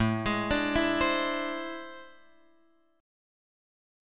AmM11 chord {5 3 6 4 3 x} chord